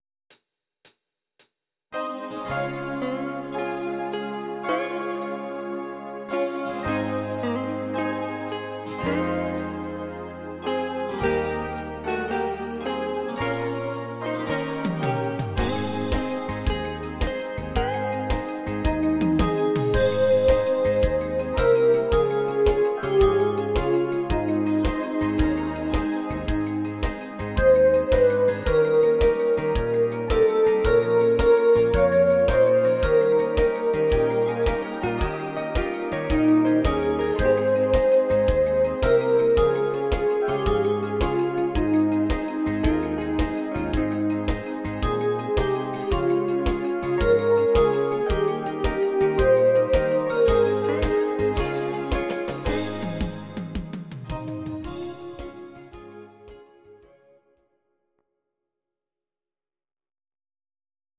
These are MP3 versions of our MIDI file catalogue.
Please note: no vocals and no karaoke included.
Hawaiigitarre